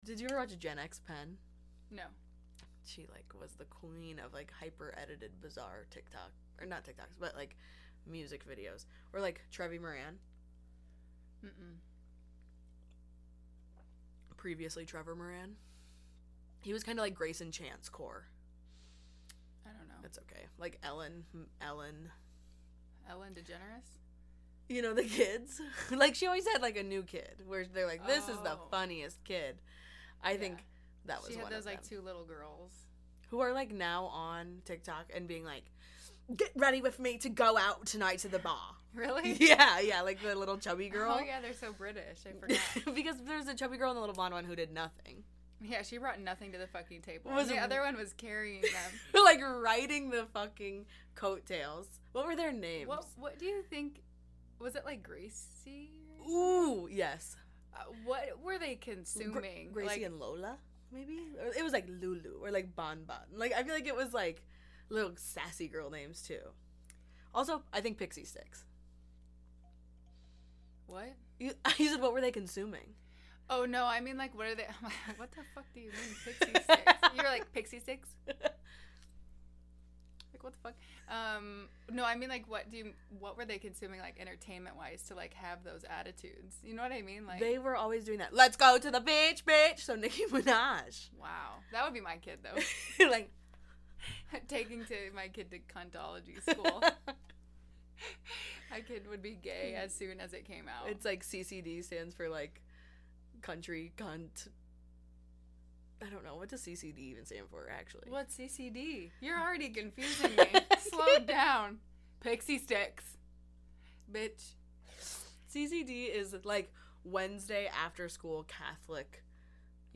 Two midwest bisexuals solve the world's problems out of a dirty Honda CRV.